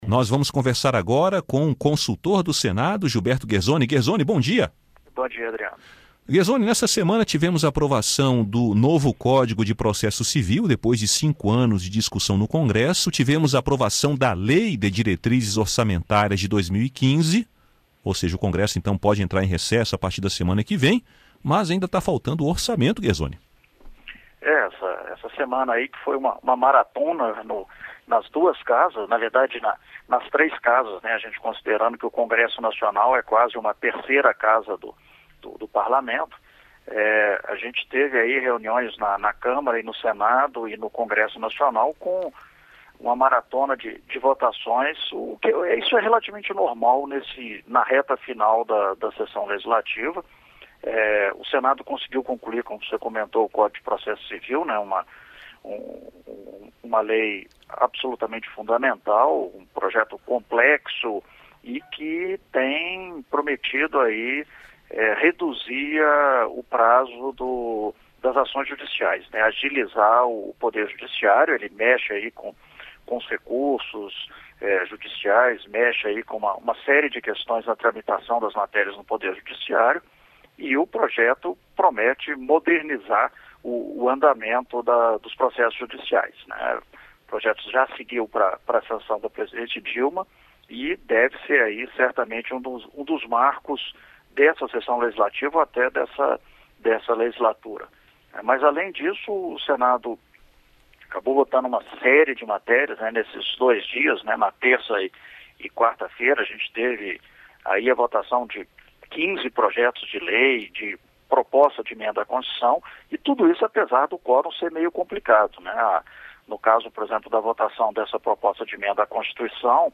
Comentário: Congresso aprova LDO de 2015